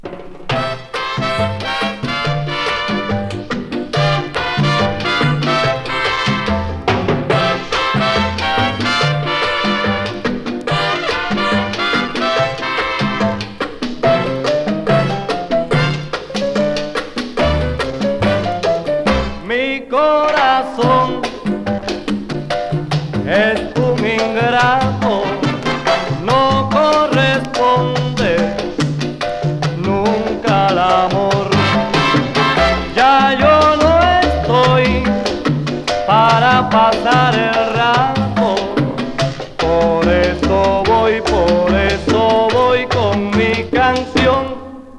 Latin, World　USA　12inchレコード　33rpm　Mono